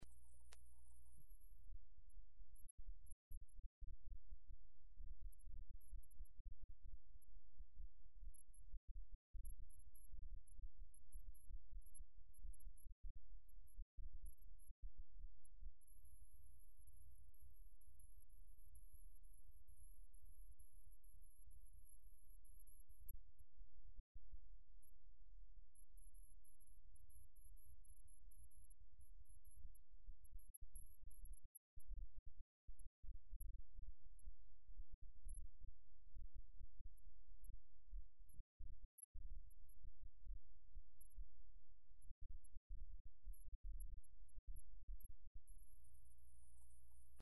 "Ei Bendith ar ei Ben", presented by the Cerddorfa Symffoni o Caeravon (mp3)
anthemllyngwyn.mp3